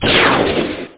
zap.mp3